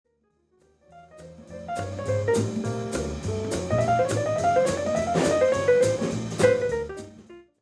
latin anthem